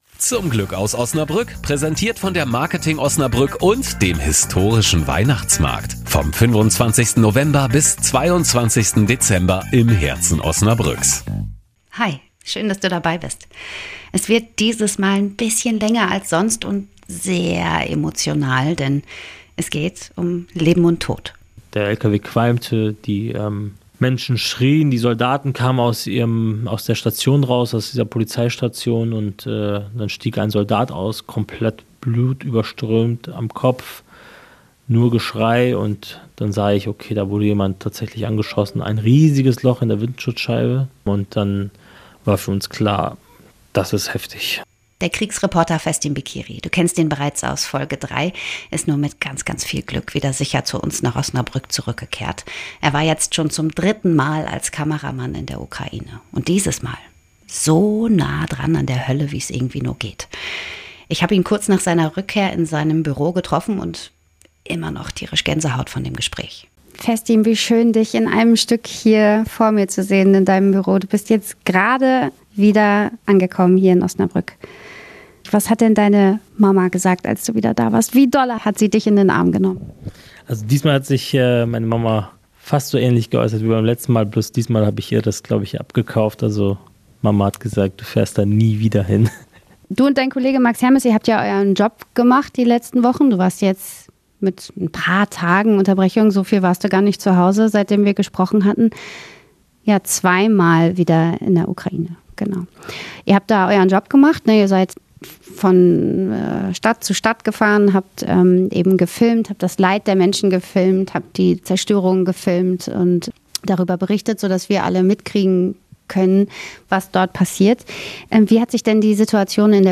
Ich habe diesmal mit zwei beeindruckenden Menschen gesprochen, die auf sehr verschiedene Art und Weise dem Tod ins Auge blicken.
Mitten im Bestattungshaus